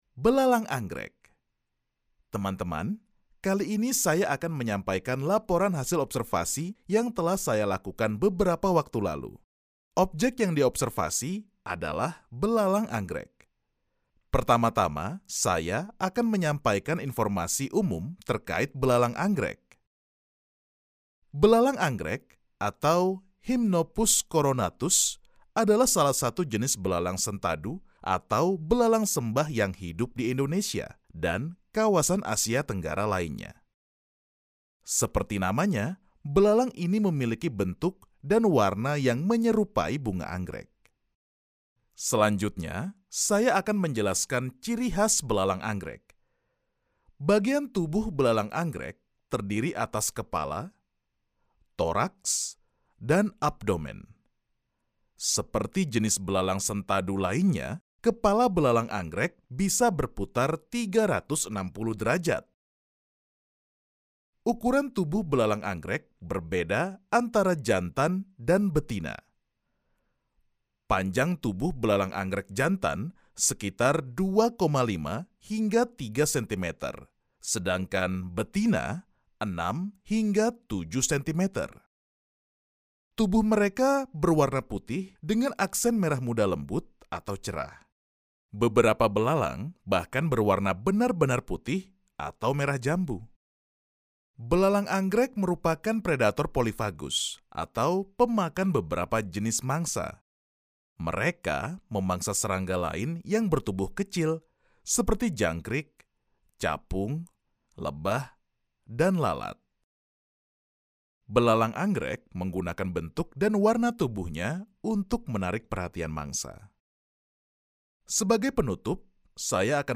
Commercial, Cool, Versatile, Mature, Warm
E-learning